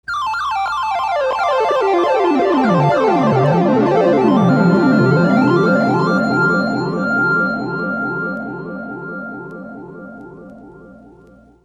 Dance - Electro